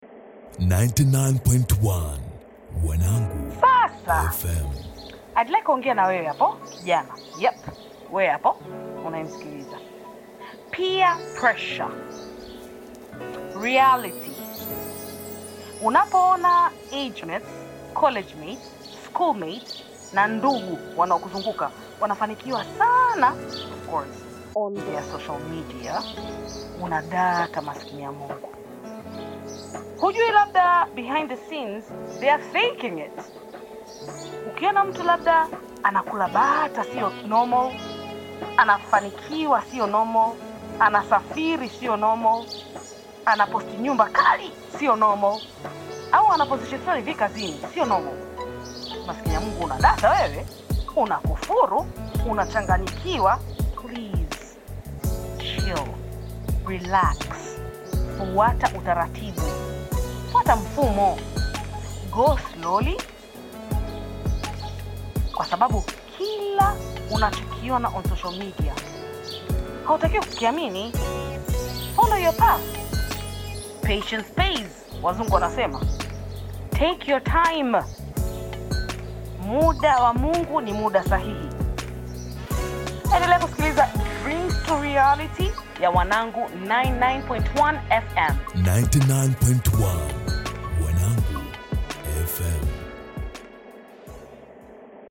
heartfelt lyricism and uplifting rhythms